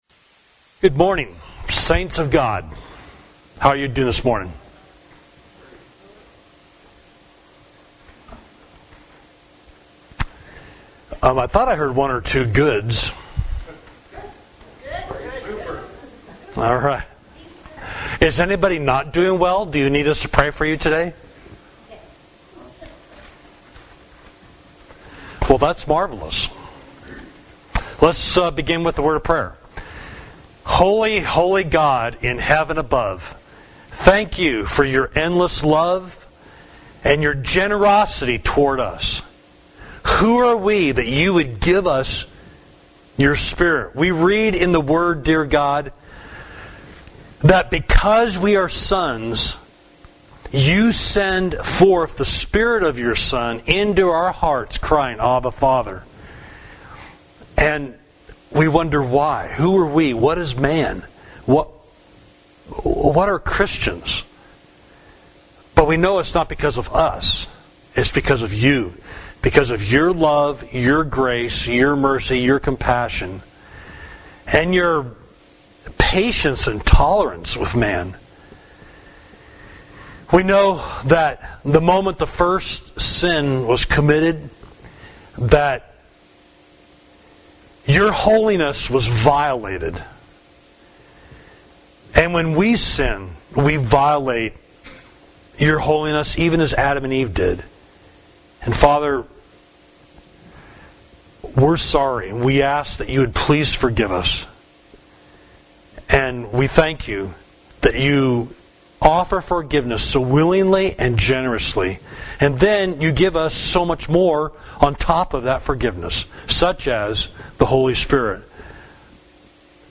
Class: The Holy Spirit in Romans 8